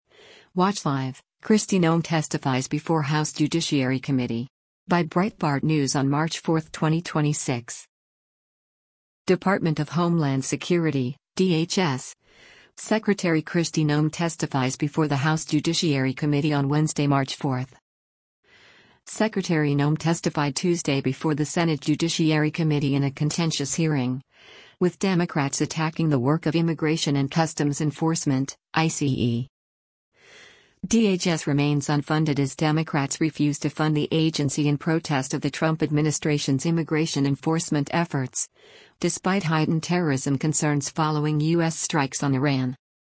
Department of Homeland Security (DHS) Secretary Kristi Noem testifies before the House Judiciary Committee on Wednesday, March 4.